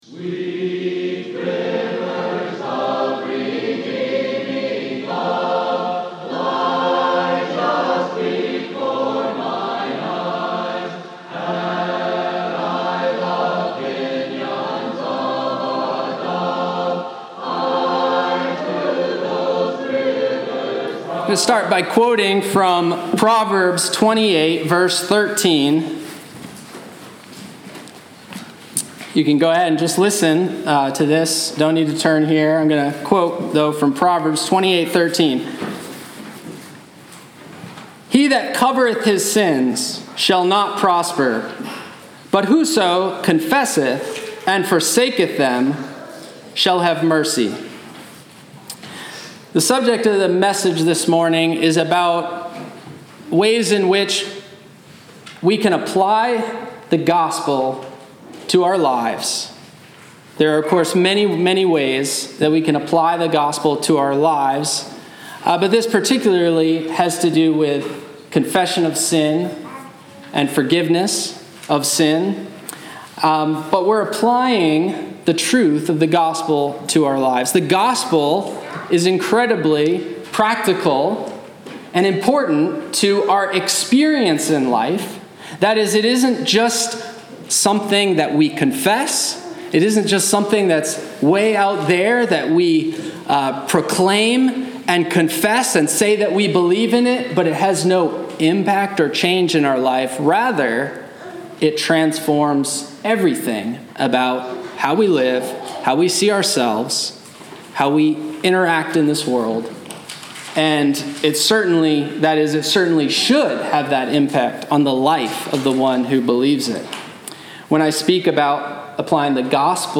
Sermoncast – The Promised Descendent